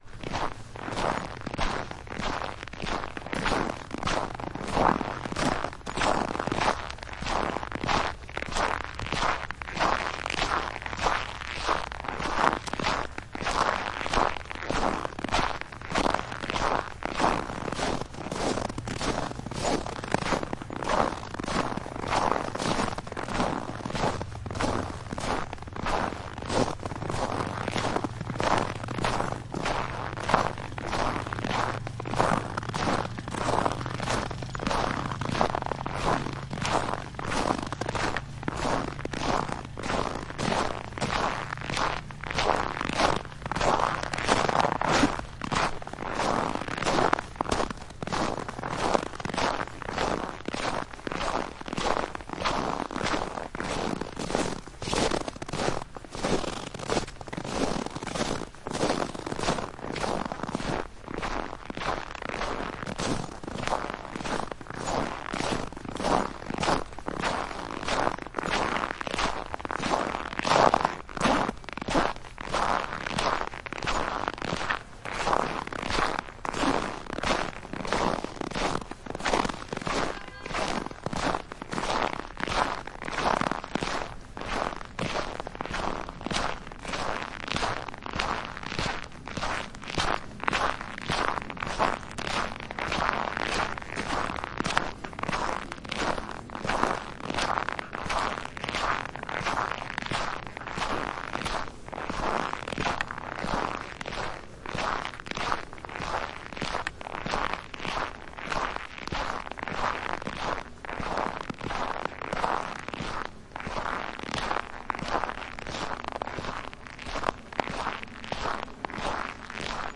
努纳维克 " 脚步声 橡皮靴 走在嘎吱作响的雪地上 肩并肩地走在大街上 向左走 向右走
描述：脚步声橡胶靴走路嘎吱嘎吱嘎吱嘎吱嘎吱嘎吱嘎吱嘎吱嘎啦
Tag: 步行路程 靴子 脚步声 橡胶